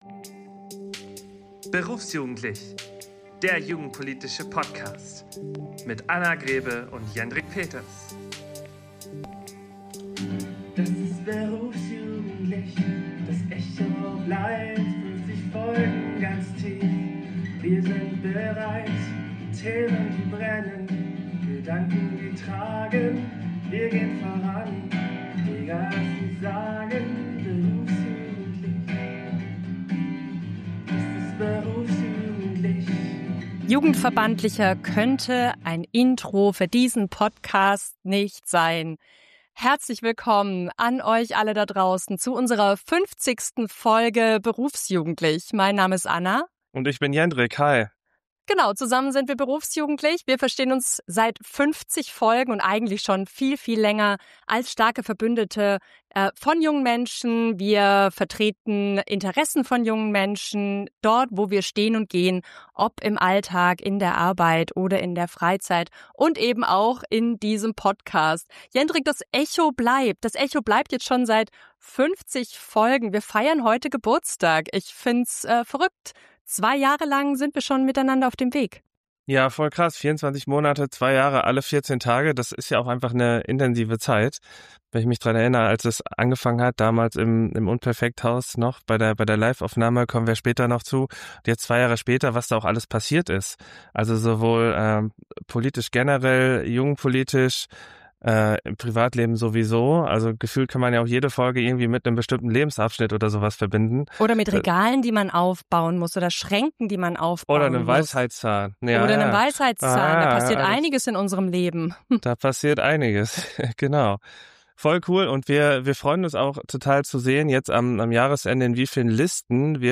Zwischendurch hört ihr viele bekannte Stimmen aus zwei Jahren Berufsjugendlich, die uns gratulieren und begleiten. Außerdem erzählen wir, wie eigentlich eine Folge entsteht – vom spontanen Themenfindungsprozess über Google Docs, WhatsApp und Wochenendaufnahmen bis zur Technik, zum Schnitt und zu Social Media.